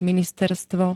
ministerstvo [-n-t-] -va -tiev s.
Zvukové nahrávky niektorých slov